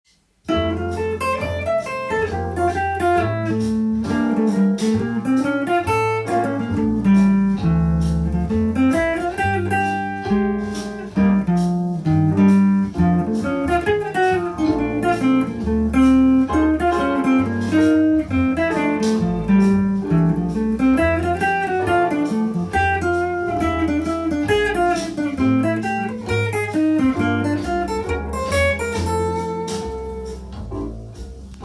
カラオケ音源にあわせて譜面通り弾いてみました
keyがFになるのでFのダイアトニックコードが多く使われていますが
転調も多い曲です。